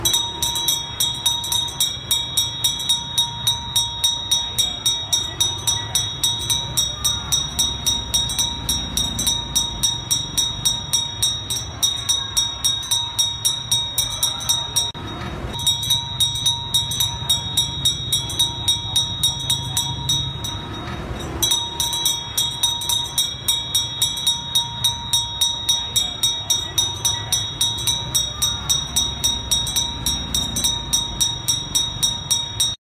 Tiếng Chuông Reng Reng rao cà rem, bán kem… ngày xưa
Thể loại: Tiếng chuông, còi
Description: Reng Reng... Leng keng… leng keng… Cà rem đây… cà rem đây… Tiếng chuông nhỏ vang vọng giữa trưa hè, mang theo cả một bầu trời tuổi thơ ùa về, nguyên vẹn như chưa từng xa....
tieng-chuong-reng-reng-rao-ca-rem-ban-kem-ngay-xua-www_tiengdong_com.mp3